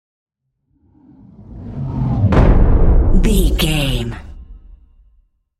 Cinematic whoosh to hit deep
Sound Effects
Atonal
dark
intense
tension
woosh to hit